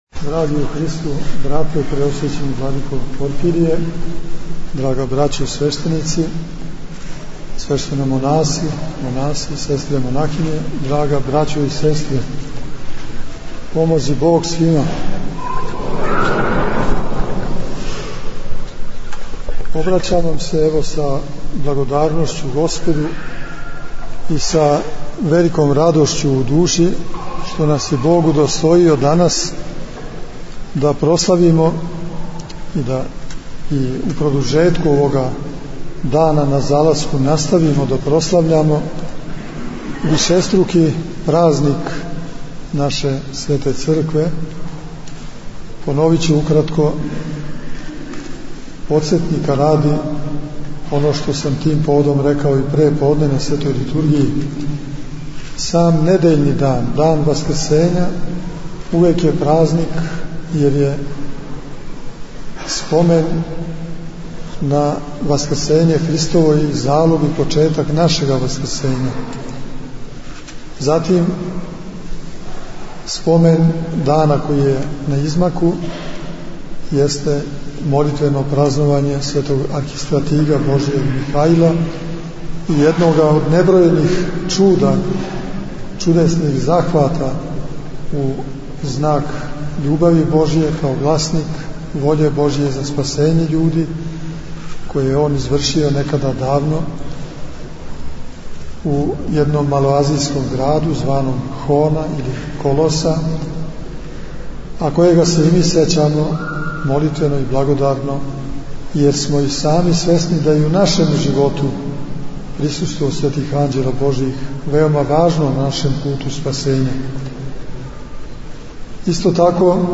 • Беседе Епископа Иринеја и Епископа Порфирија: